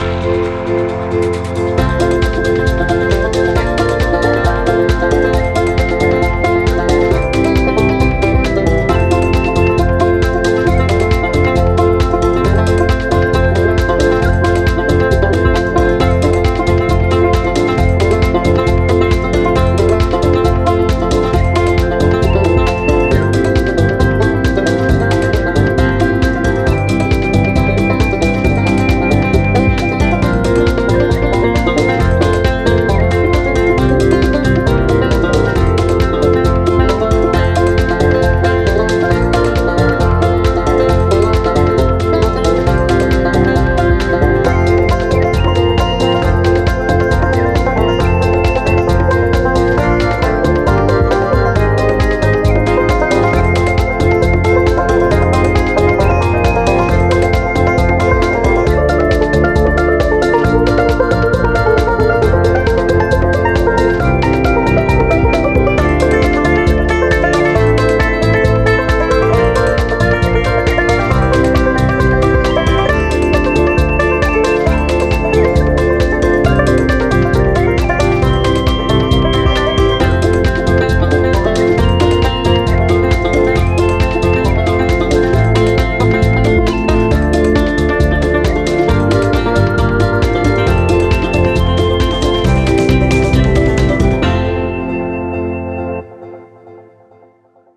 But I searched around for some inspo on Loopcloud, found the banjos and the rest just kinda fell into place.